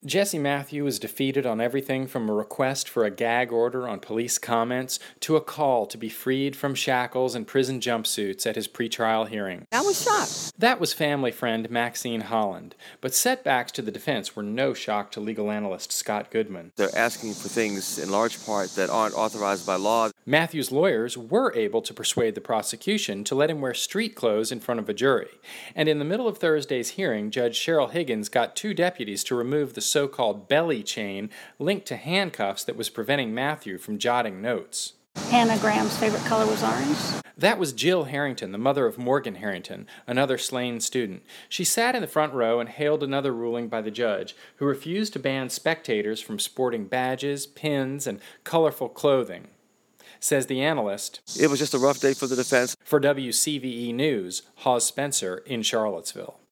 For WCVE News